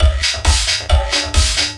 循环20
描述：鼓声循环，有一种叫做振幅调制的效果，又是两三年前在ACID1.0上创作的。